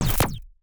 UIClick_Menu Double Hit Rumble Tail 03.wav